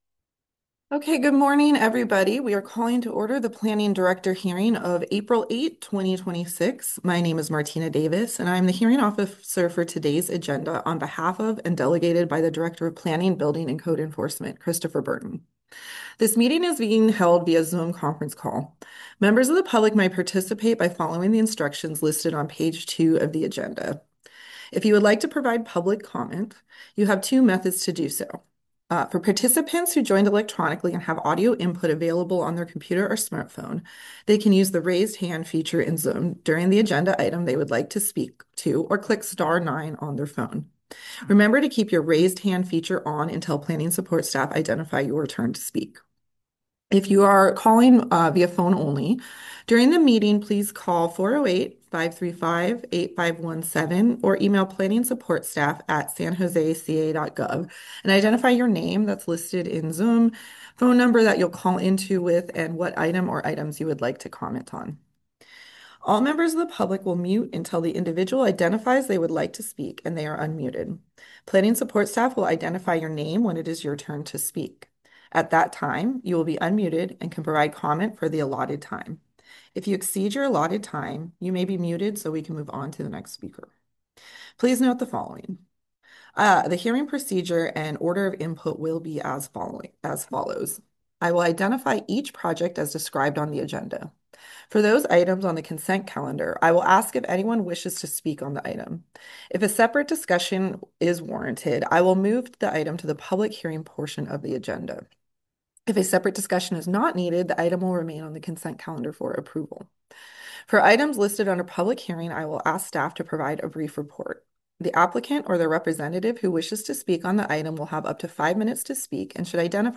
You'll hear authentic audio capturing the voices of city officials, community leaders, and residents as they grapple with the local issues of the day. This podcast serves as an archival audio record, providing transparency and a direct line to the workings of local government without editorial polish.